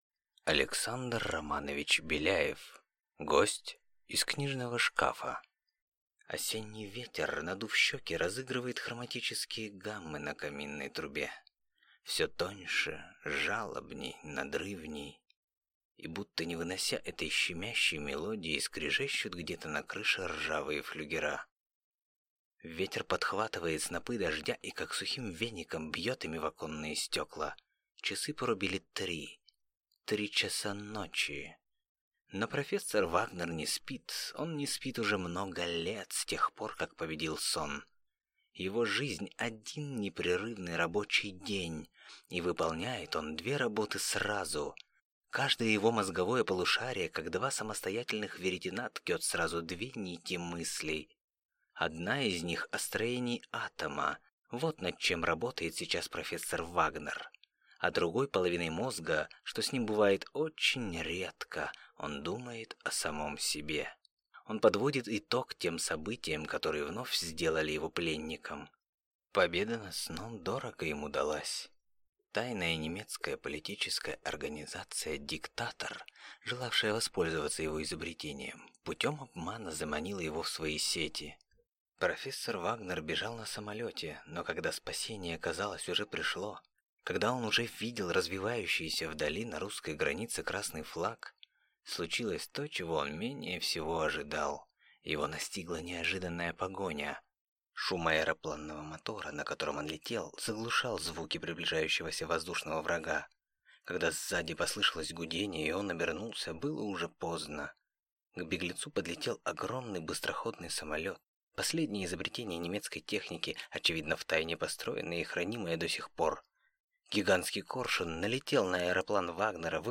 Аудиокнига Гость из книжного шкафа | Библиотека аудиокниг